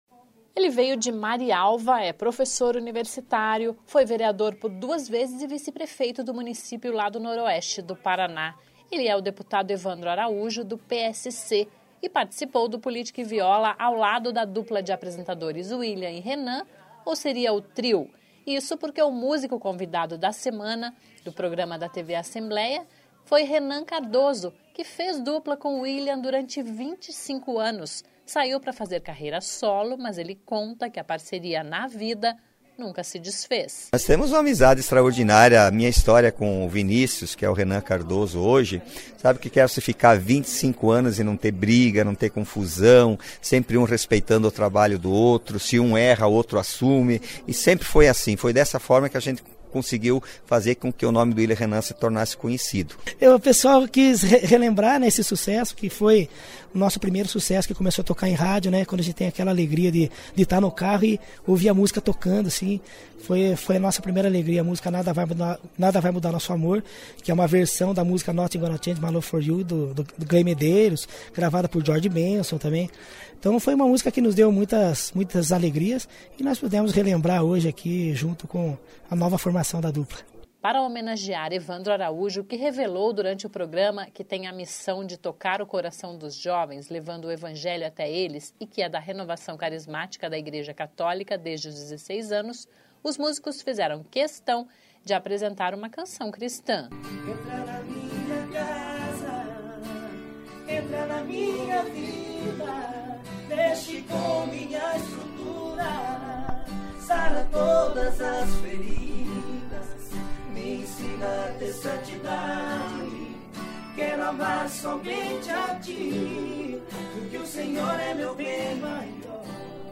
Ele é o deputado Evandro Araújo (PSC).